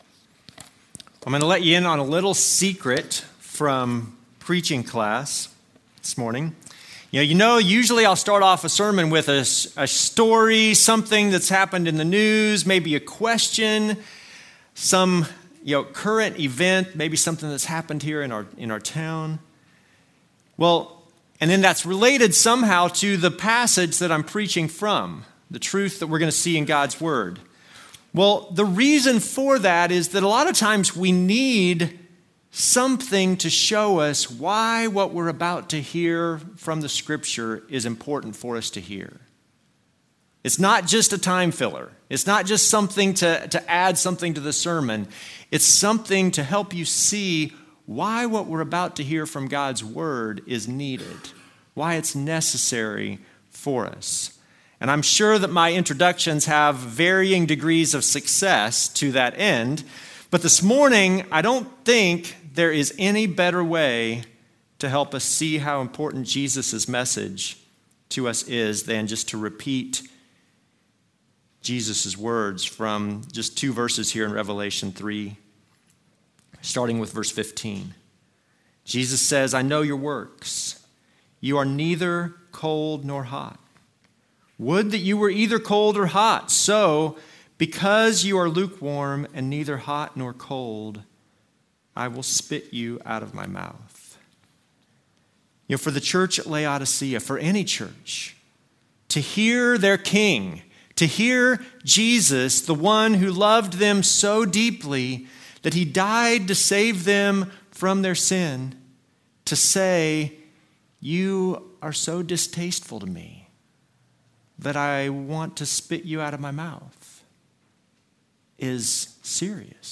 Service Type: Normal service